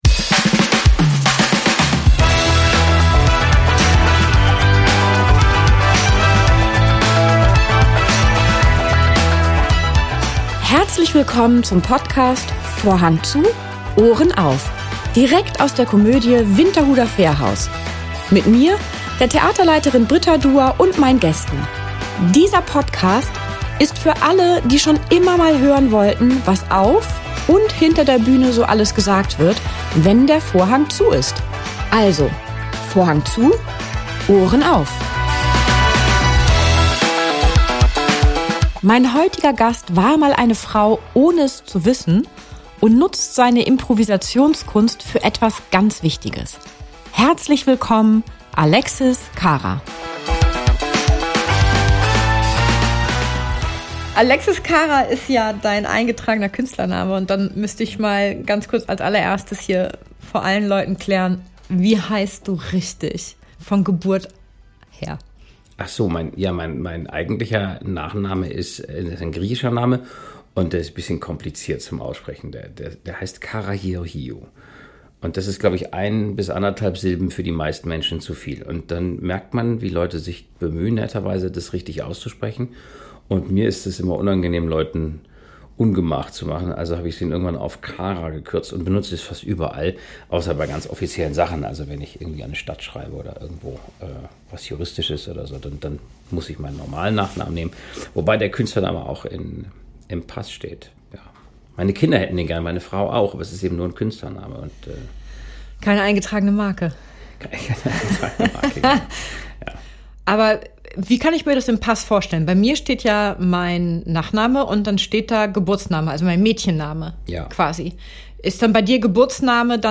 #92 mit Schauspieler Alexis Kara ~ Vorhang zu, Ohren auf! Der Talk aus der Komödie Winterhuder Fährhaus Podcast